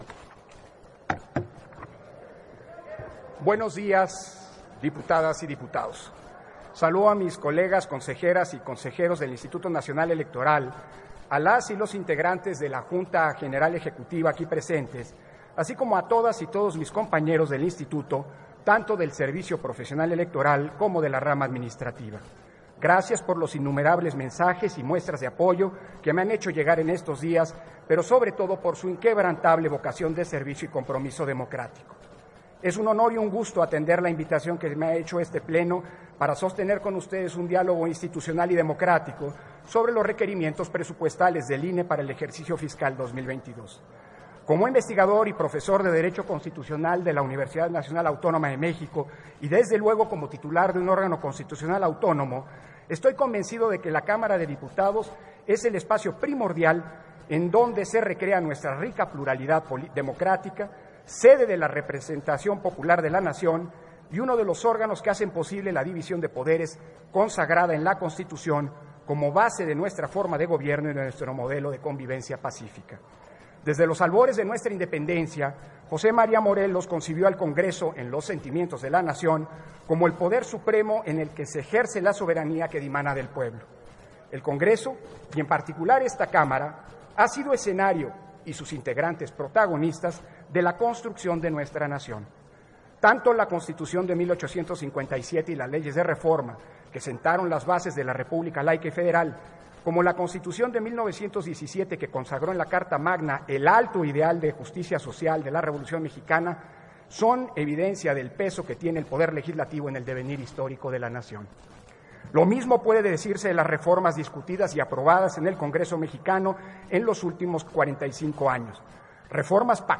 Presentación de Lorenzo Córdova, del anteproyecto de Presupuesto del INE para el ejercicio 2022 ante la Cámara de Diputadas y Diputados